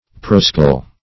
Prosaic \Pro*sa"ic\, Prosaical \Pro*sa"ic*al\, a. [L. prosaius,